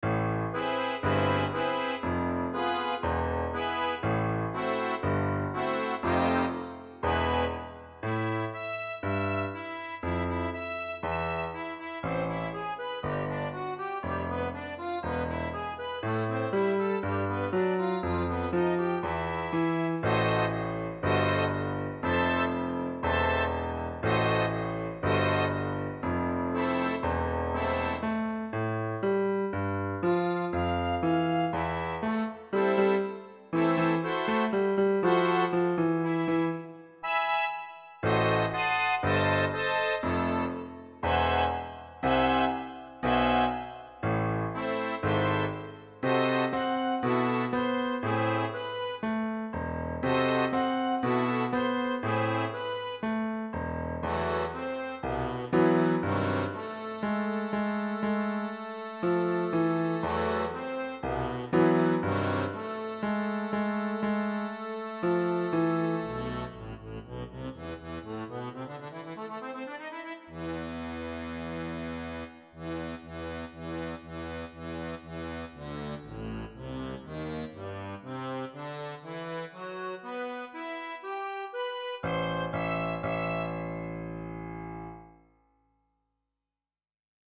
SOFT AMBIENT VERSION
GenereWorld Music
Jazz club full of smoke and soft relaxing atmosphere